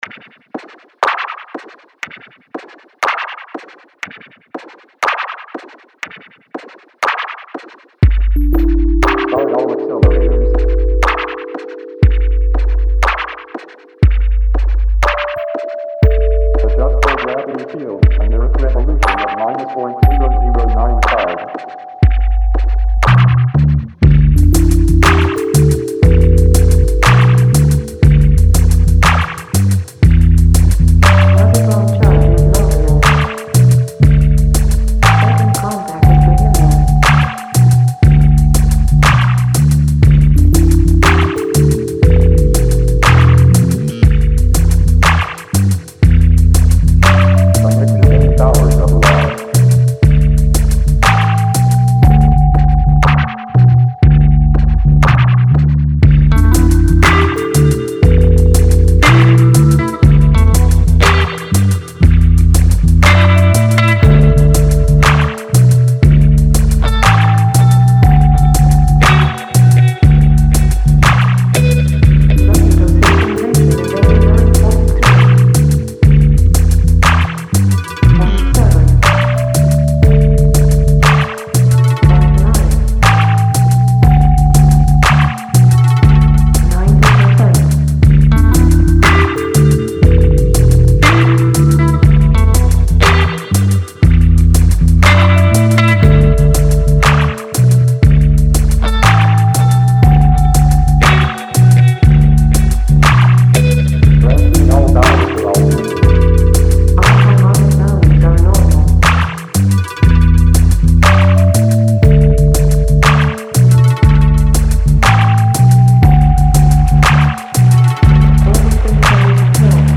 Wow there is a lot going on here.